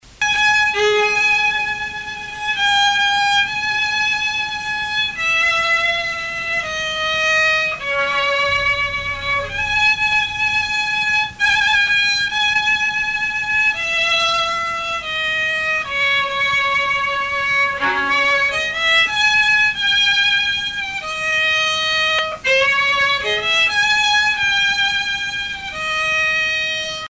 danse : polka
Pièce musicale inédite